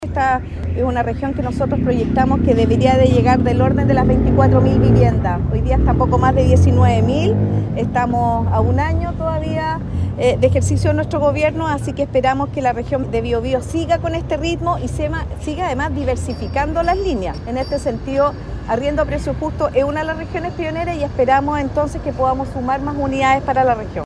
Por otro lado, el Plan de Emergencia Habitacional se cumplió en un 100% en la Región del Biobío y las autoridades adelantaron una nueva meta: llegar a 24 mil o más viviendas de aquí a diciembre. La subsecretaria Elgueta sostuvo que “trabajaremos hasta el último día”.
Arriendo-Justo-4-Subsecretaria.mp3